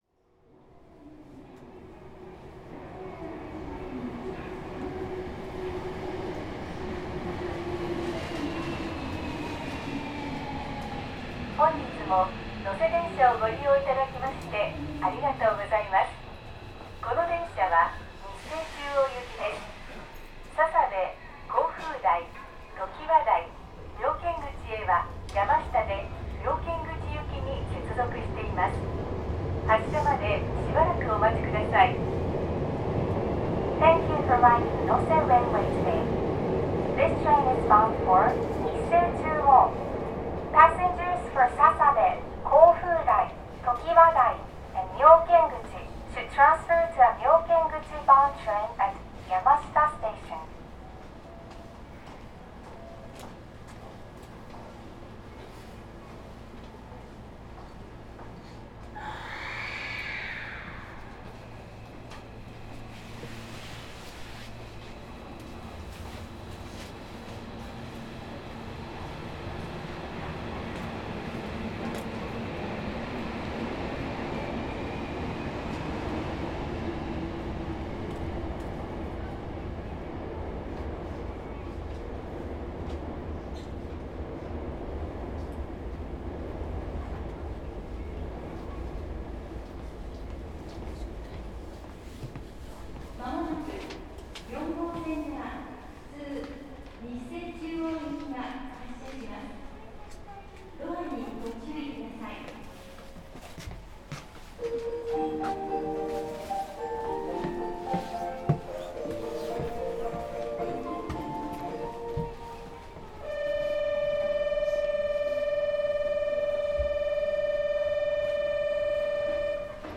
能勢電鉄 1500系 ・ 走行音(全区間) (35.0MB*) 収録区間：妙見口→川西能勢口 制御方式：抵抗制御 主電動機：SE-573B(100kW) 元阪急宝塚線2100系。
走行音は元阪急2000系の1700系と比べると、甲高い音が目立つ。